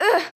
damage2.wav